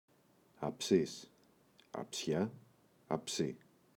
αψύς [a’psis]
αψύς-αψιά-αψύ.mp3